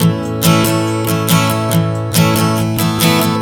Strum 140 A 06.wav